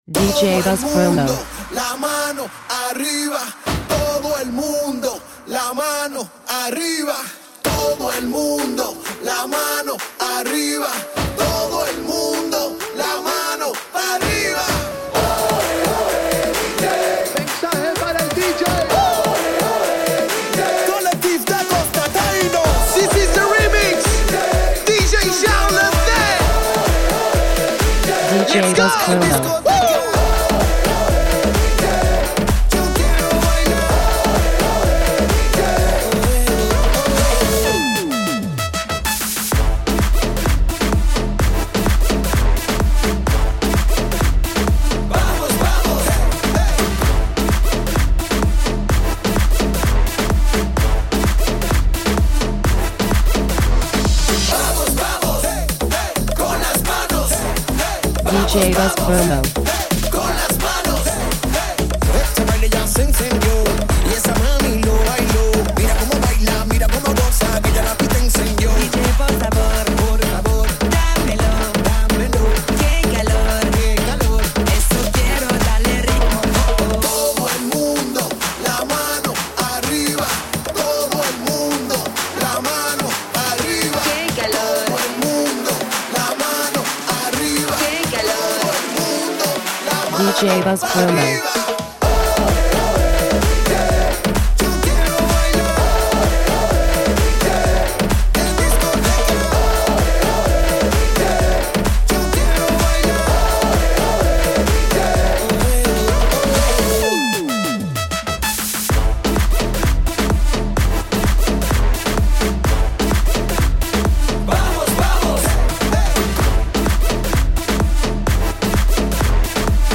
fresh Reggaeton soldiers.